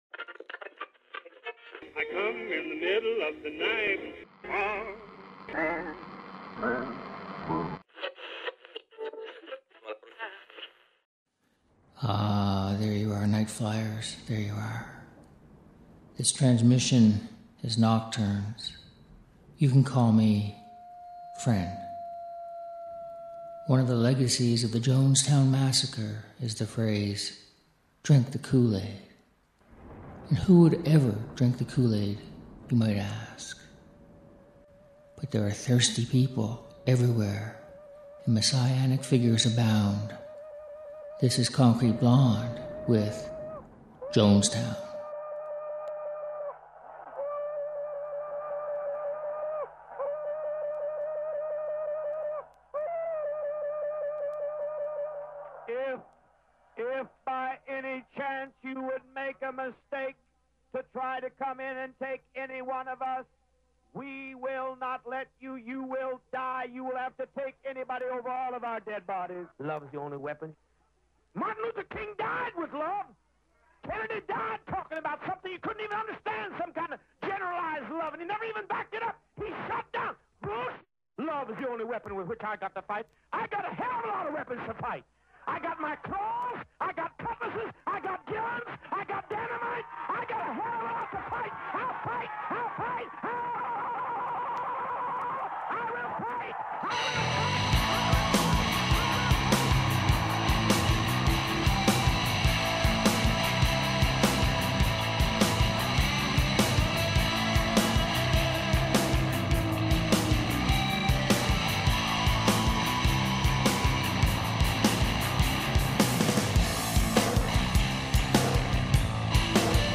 Music for nighttime listening.